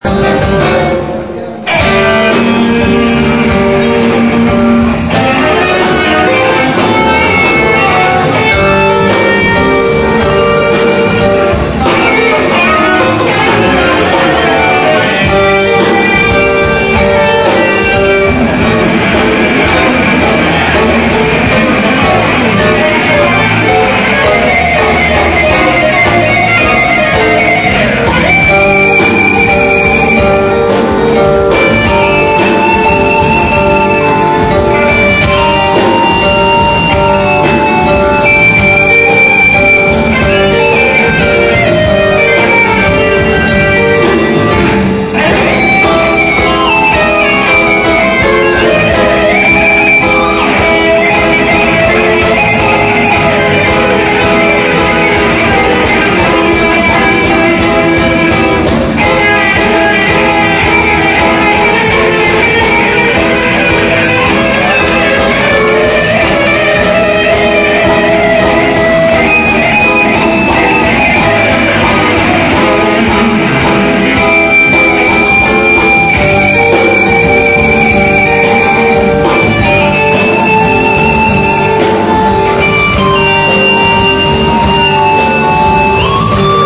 EN VIVO!!!